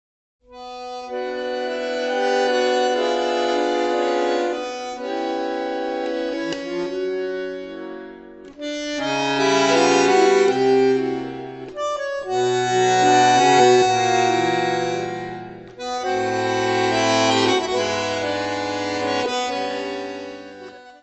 piano, congas
violino
contrabaixo
Bandoneón
: stereo; 12 cm
Music Category/Genre:  World and Traditional Music